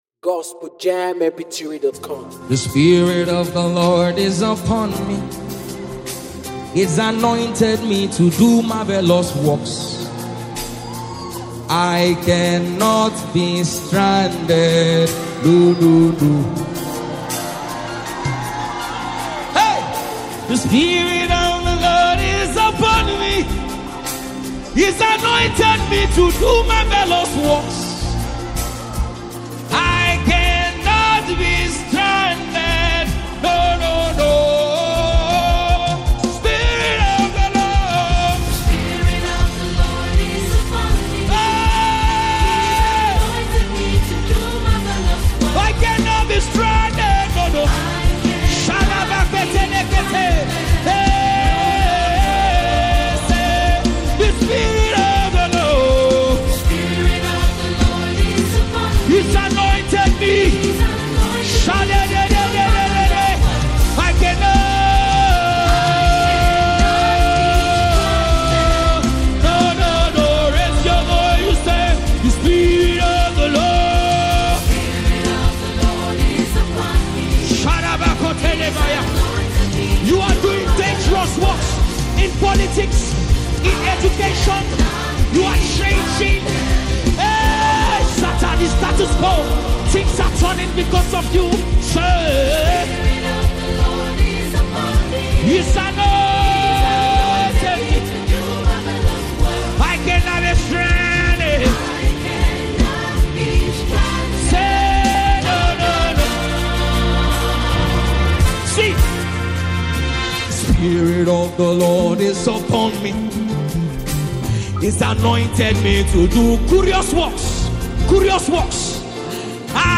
musicWorship
faith-filled gospel anthem
energetic style and uplifting sound